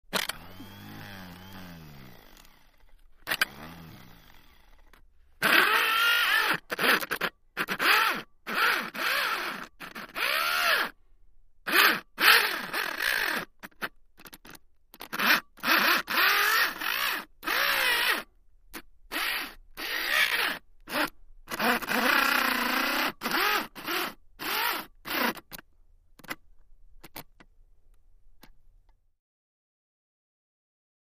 Servo Small Broken; On, Runs With Bad Motor Burns, Off